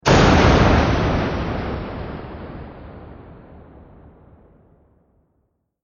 دانلود صدای بمب 10 از ساعد نیوز با لینک مستقیم و کیفیت بالا
جلوه های صوتی